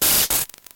Cri de Bargantua dans Pokémon Noir et Blanc.